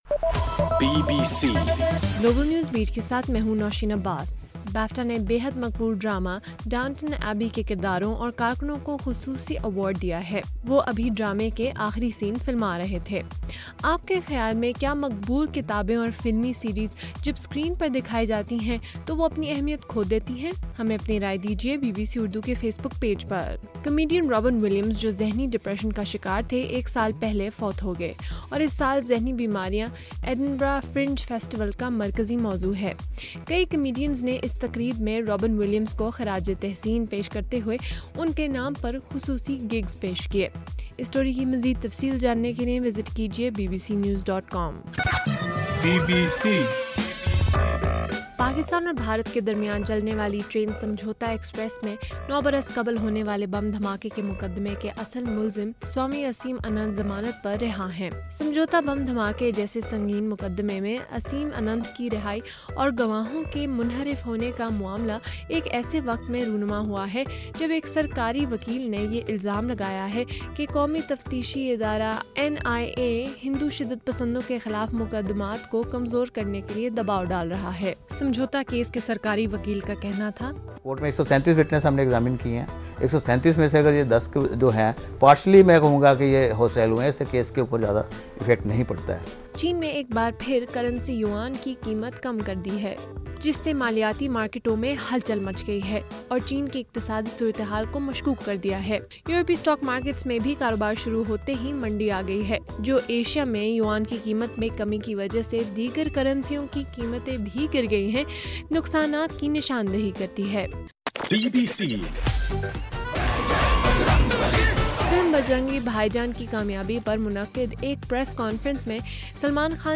اگست 12: رات 8 بجے کا گلوبل نیوز بیٹ بُلیٹن